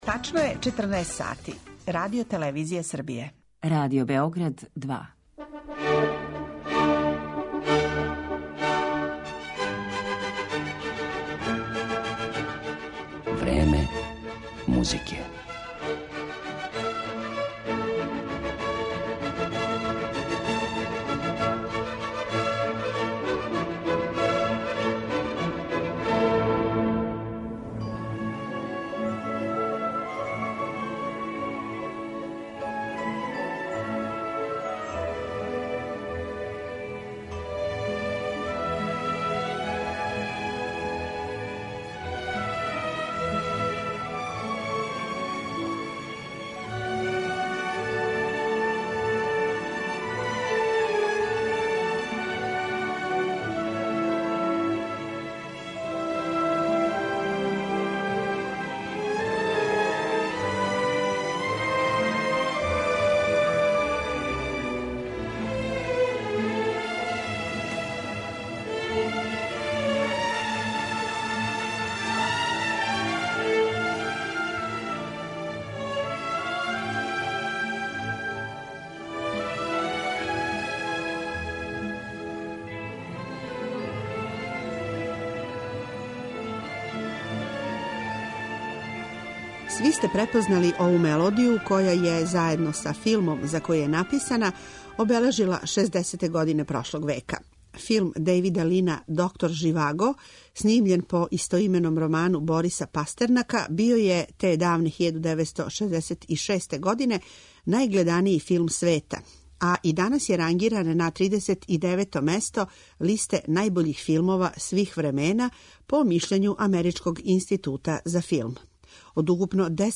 музику за филмове